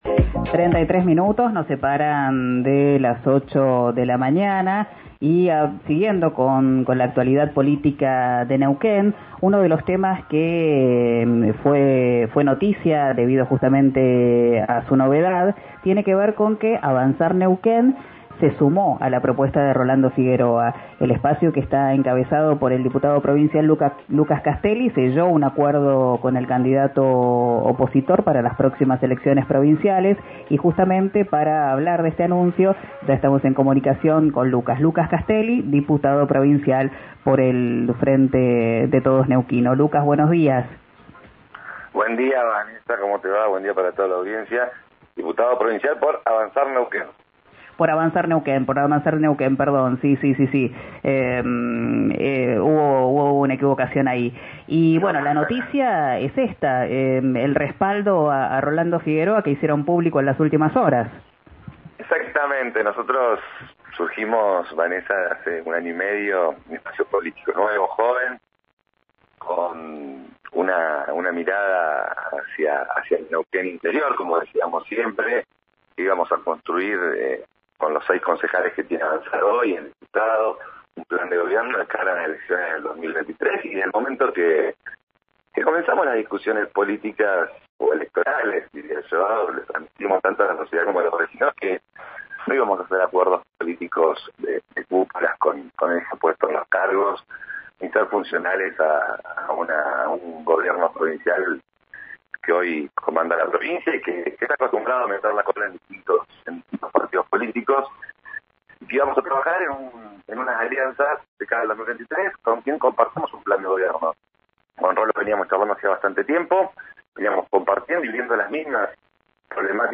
Escuchá al diputado de Avanzar, Lucas Casteilli, en «Quién dijo verano», por RÍO NEGRO RADIO: